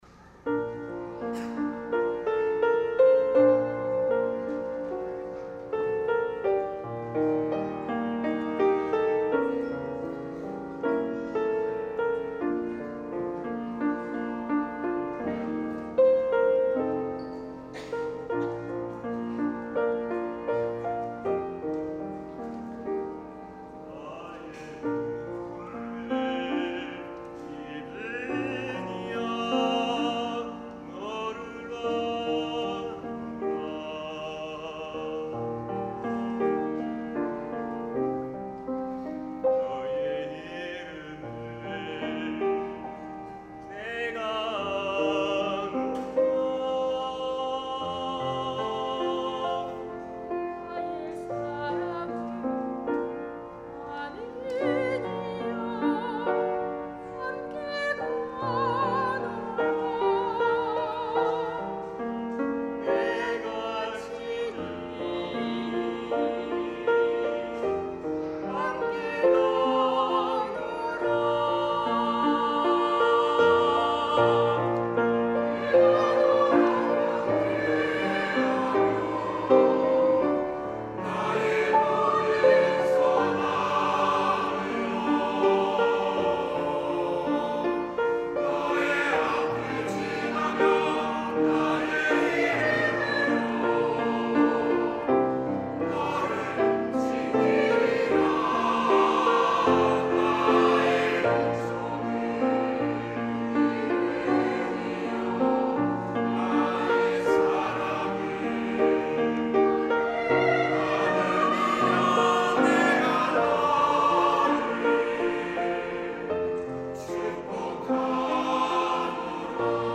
찬양 :: 140907 축복하노라
" 축복하노라 "-시온 찬양대